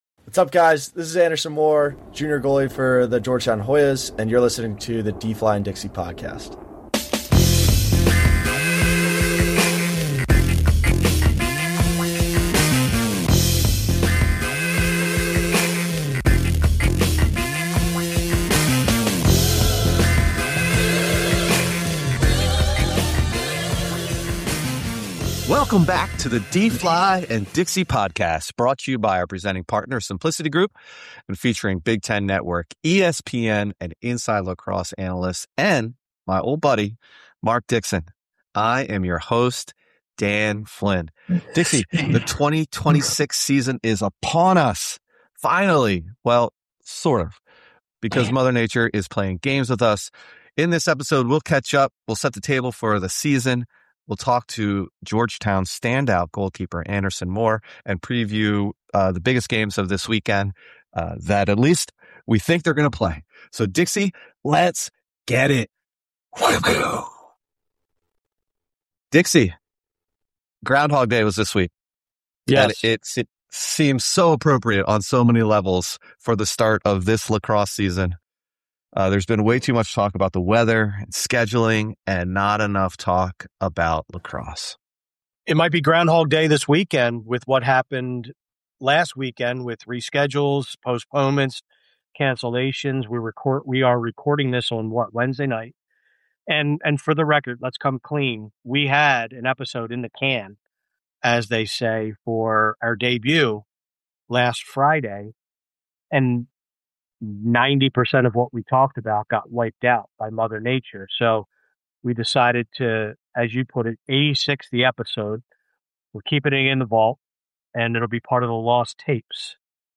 There are still many games to look forward to this weekend, and the guys preview the top matchups as usual, set the table for the season and interview a fascinating guest.